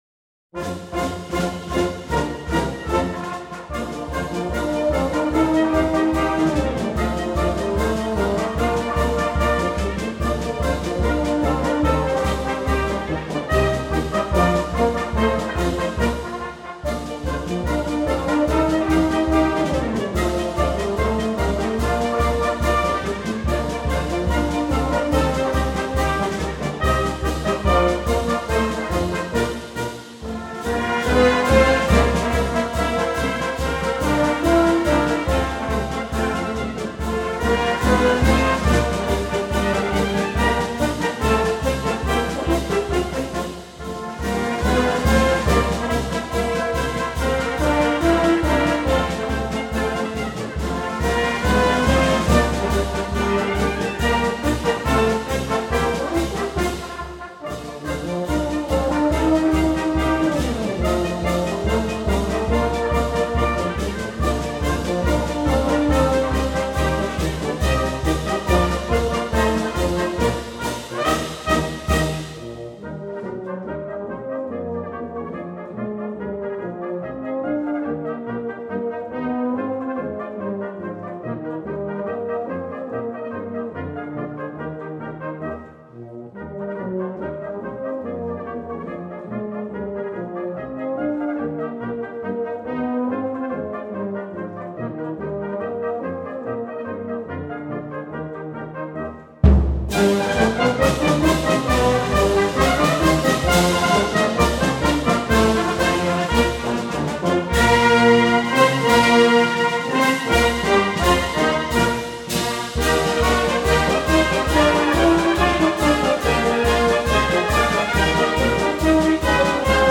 Folk Music from French-Speaking Switzerland
Fanfare Amicale-Vudallaz d’Albeuve/Enney